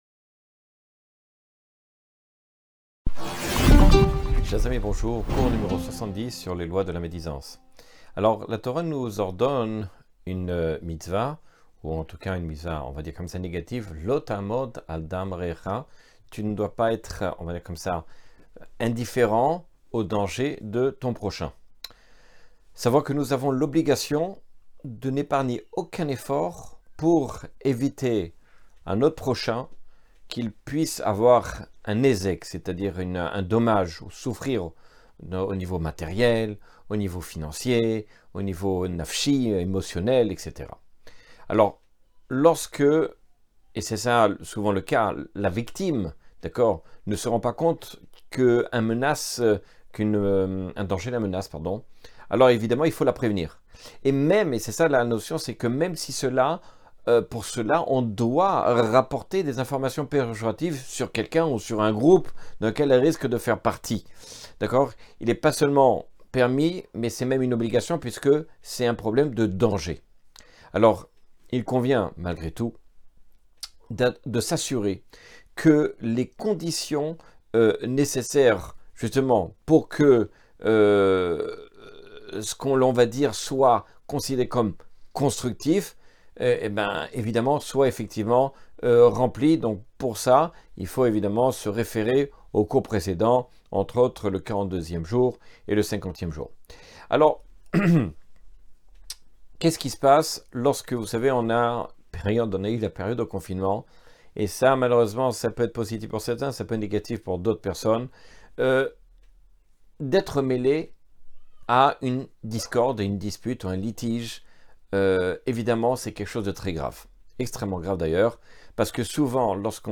Cours 70 sur les lois du lashon hara.